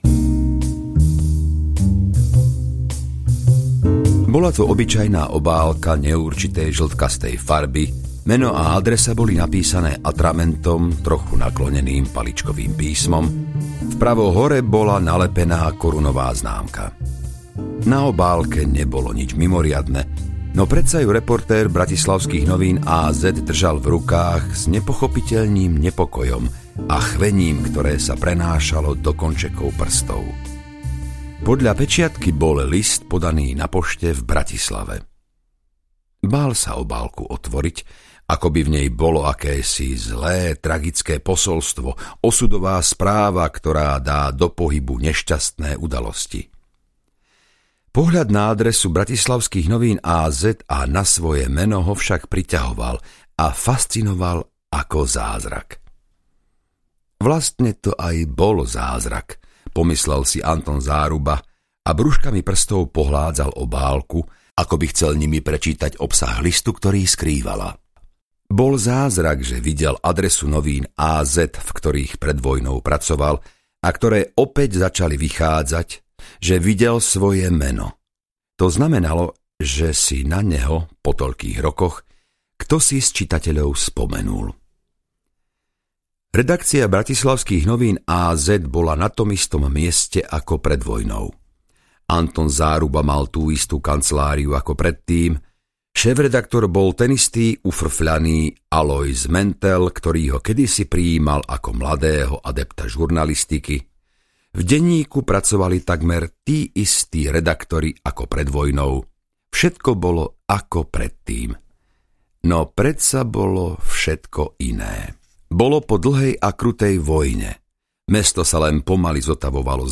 Ukázka z knihy
ja-som-niekto-iny-audiokniha